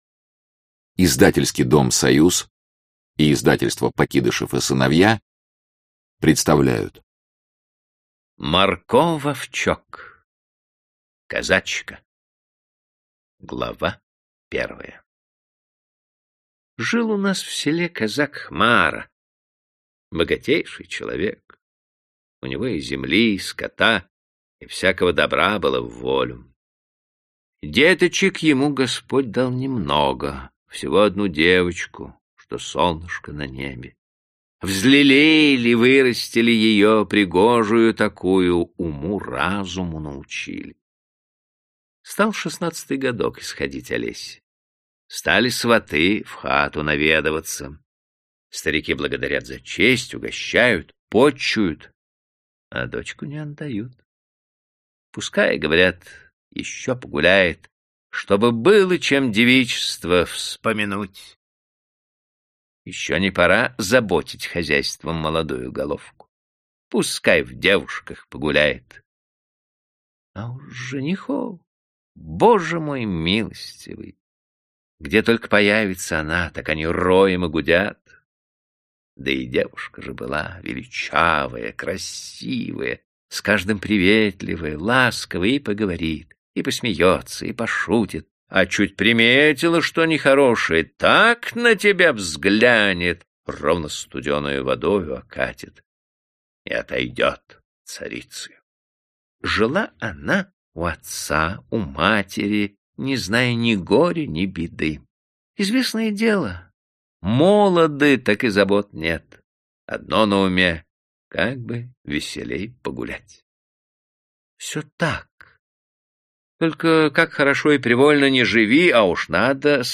Аудиокнига Козачка | Библиотека аудиокниг